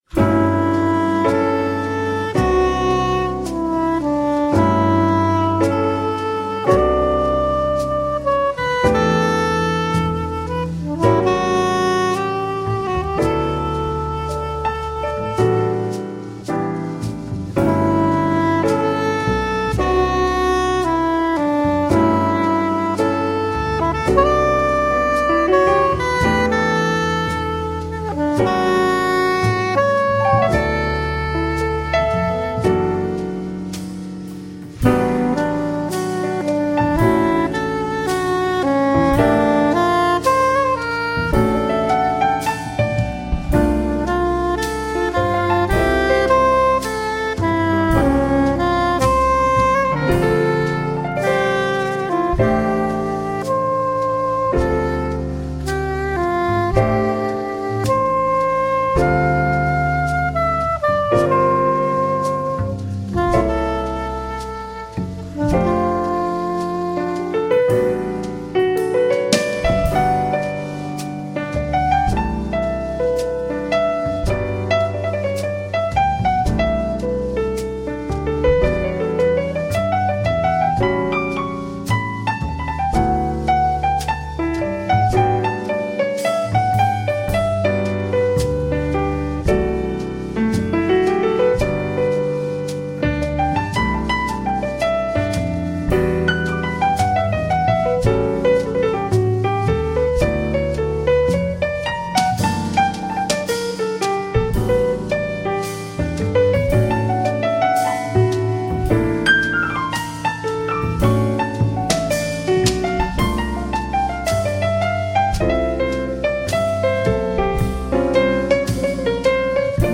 Gentle jazz from sweden.
piano
soft and beautiful
Tagged as: Jazz, Blues